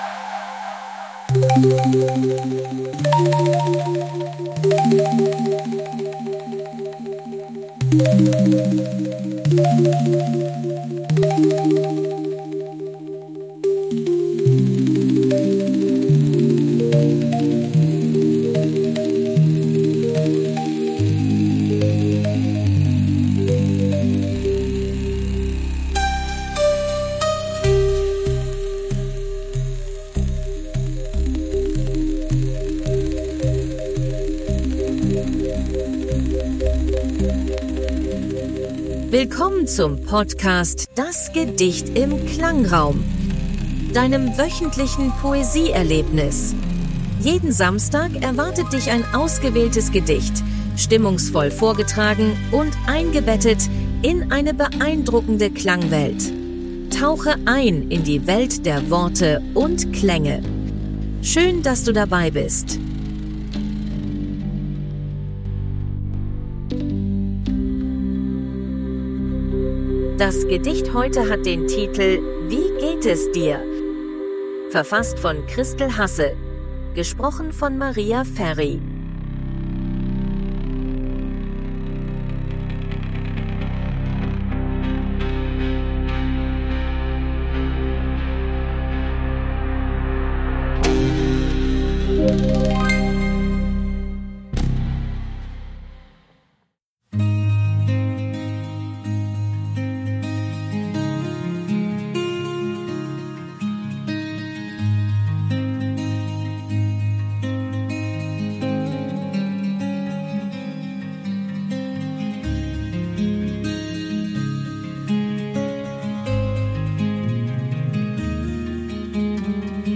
Klangraummusik: KI generiert.